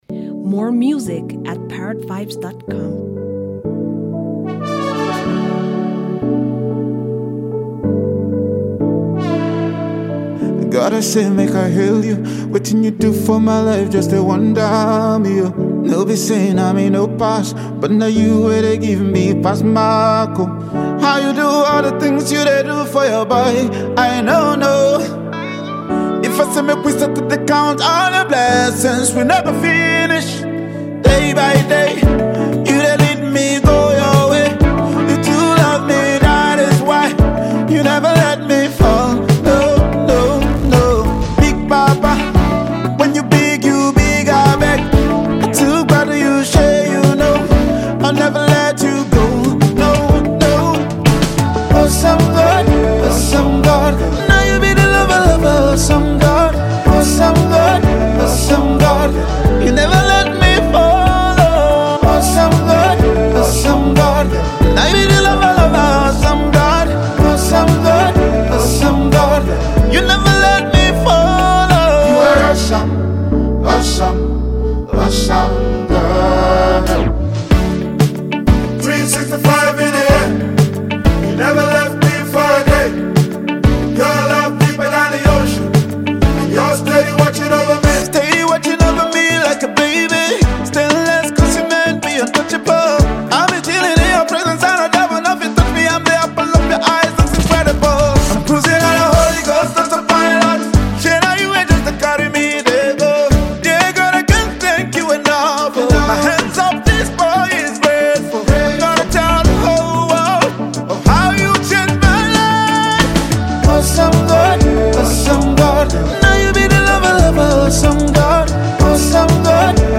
Renowned Nigerian gospel singer and music producer
soul-stirring